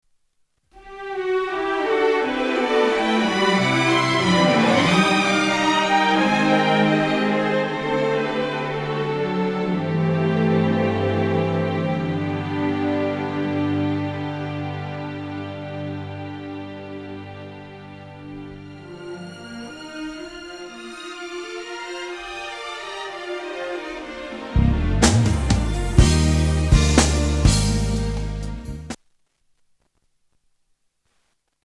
STYLE: R&B
The sweet ballad